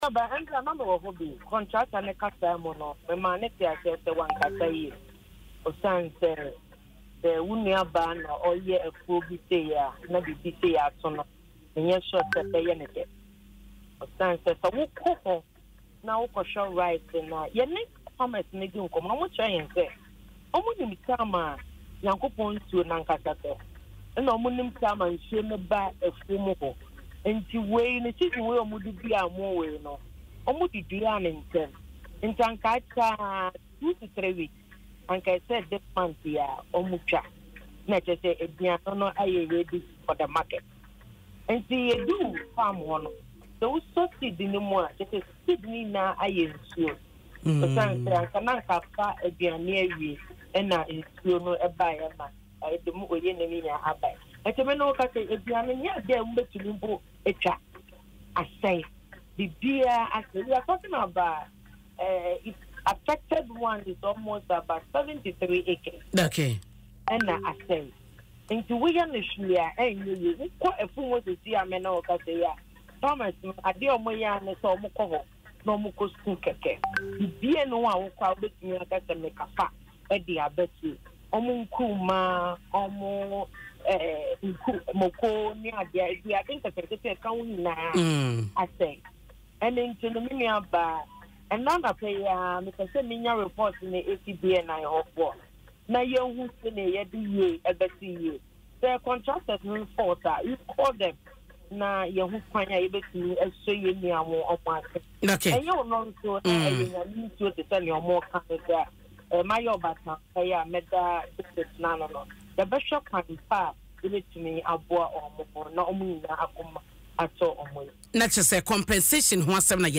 Madam Ackom said this in an interview on Adom FM’s Dwaso Nsem, expressing sadness over the loss of livelihoods.